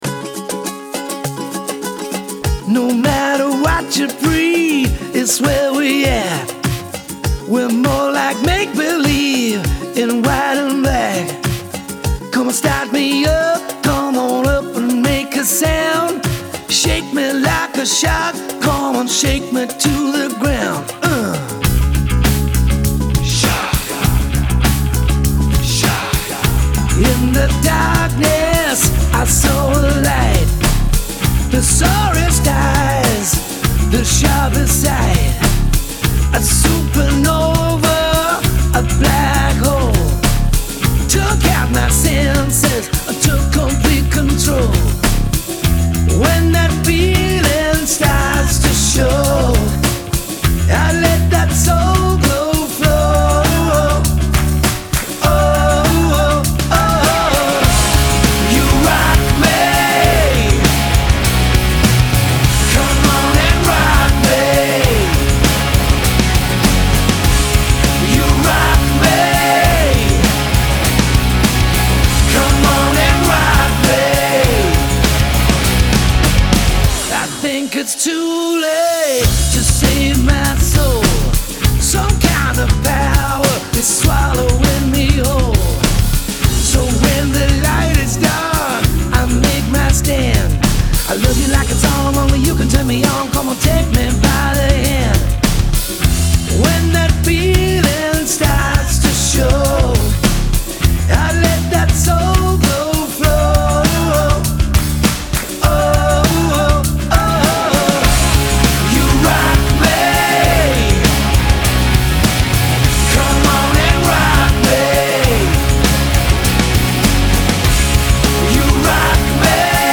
Genre : Rock, Pop